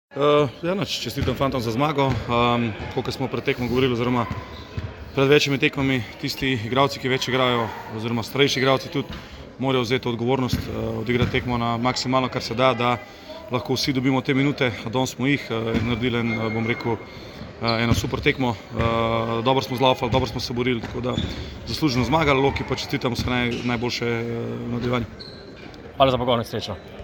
Izjava po tekmi: